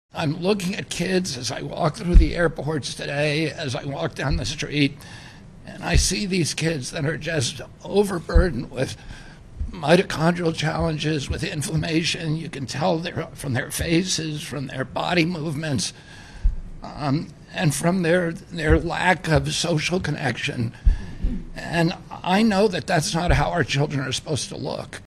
The Health and Human Services Secretary said he assesses children in his head when he passes them on the street or at an airport. Kennedy spoke alongside Texas Governor Greg Abbott at the signing of the Make America Healthy Again bill.